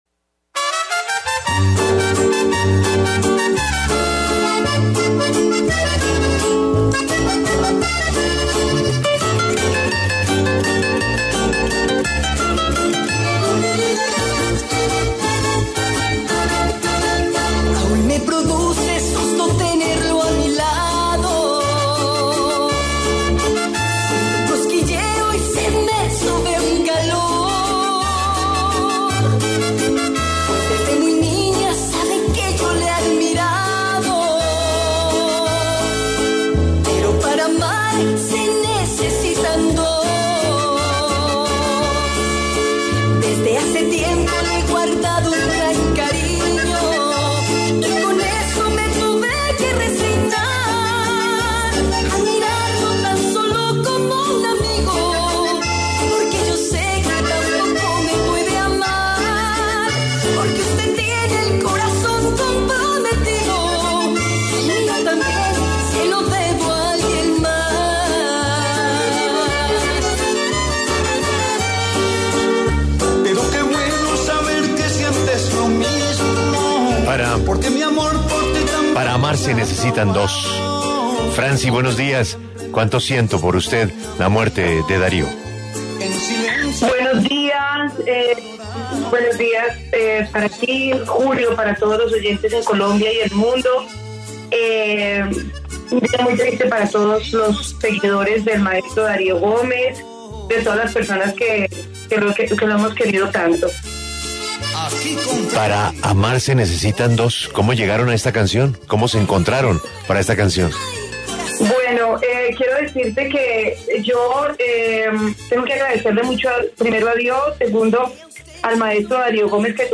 Escuche la entrevista a la cantante colombiana Francy en La W: